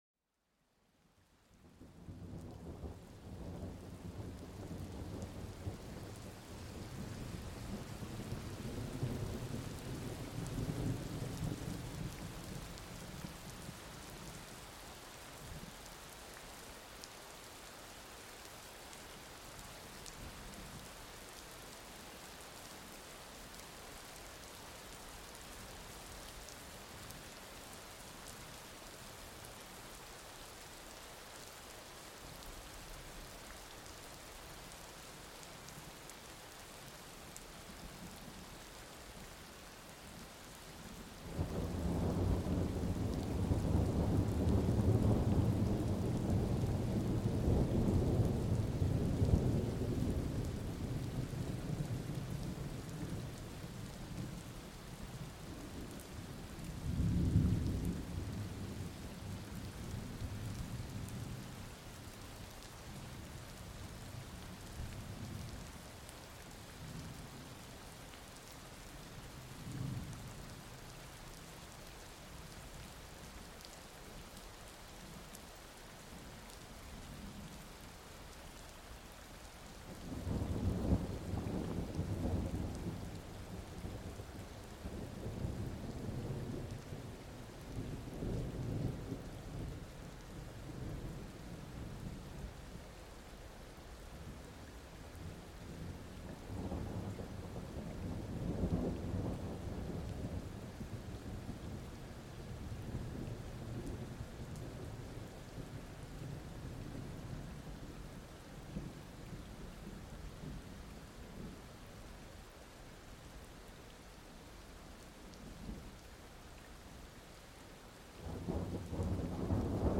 Lluvia de tormenta para un sueño profundo
El trueno retumba en la distancia mientras la lluvia continúa, creando una atmósfera apacible.